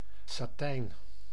Ääntäminen
Vaihtoehtoiset kirjoitusmuodot (vanhentunut) satan Ääntäminen US : IPA : [ˈsæ.tɪn] Haettu sana löytyi näillä lähdekielillä: englanti Käännös Ääninäyte Substantiivit 1. satijn Määritelmät Adjektiivit Semi - glossy .